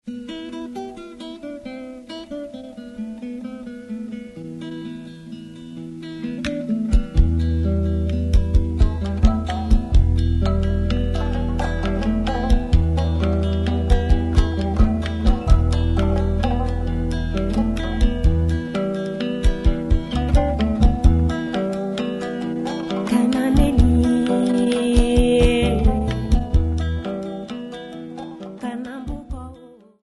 Popular music--Africa, West
96000Hz 24Bit Stereo